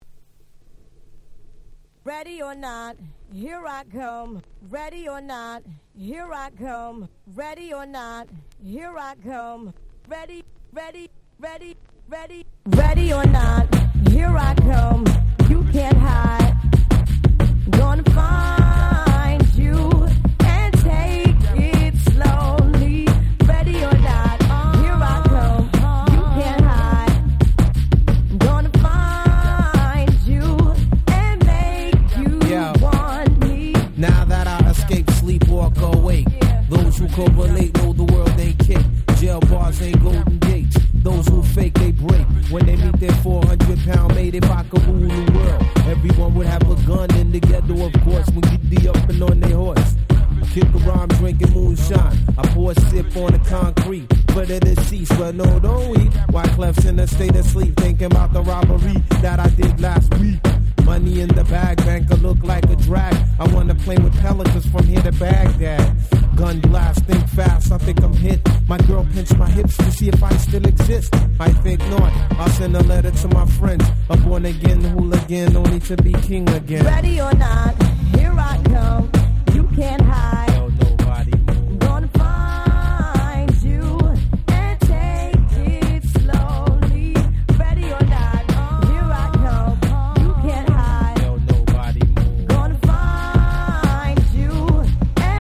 96' Super Hit R&B !!